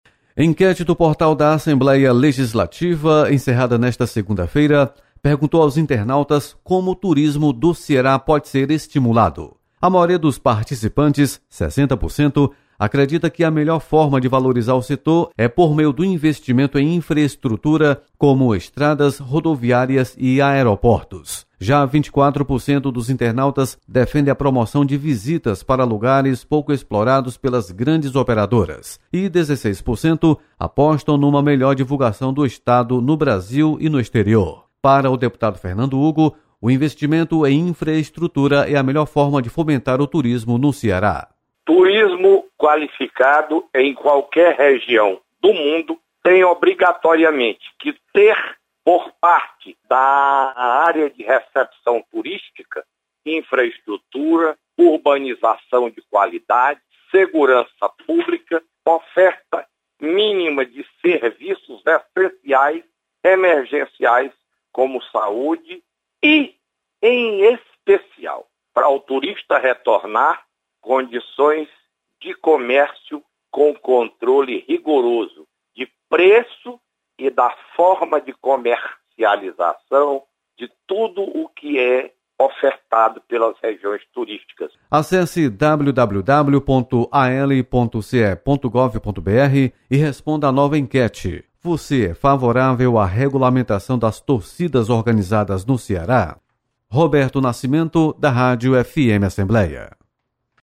Internautas apoiam investimento em infraestrutura para fomentar turismo. Repórter